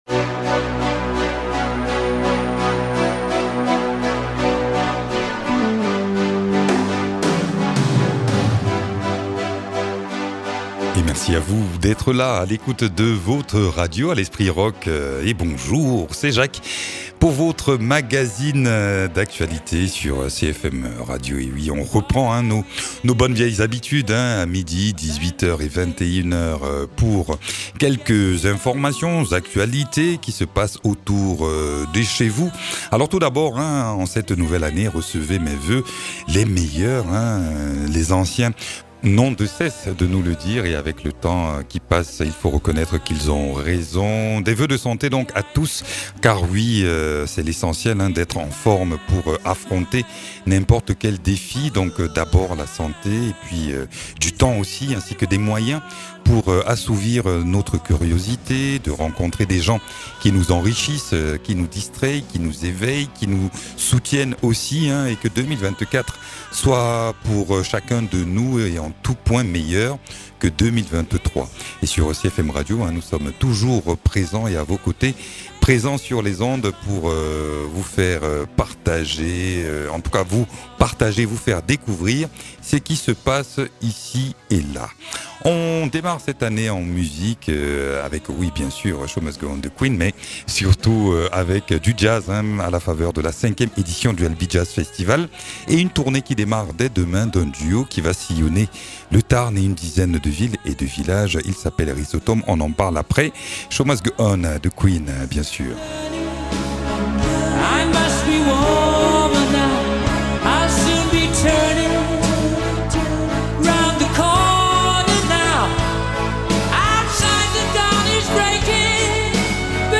Il et elle puise dans le répertoire des musiques traditionnelles et populaires pour faire retentir un jazz empreint d’universalité.